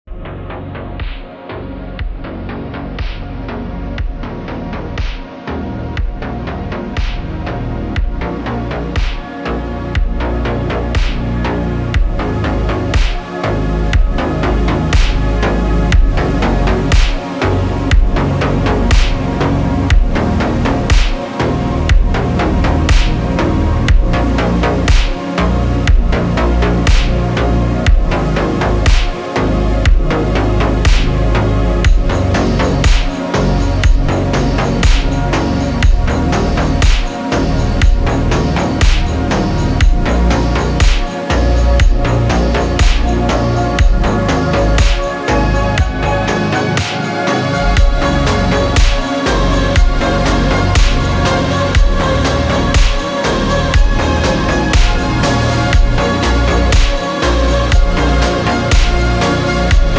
sad song 😔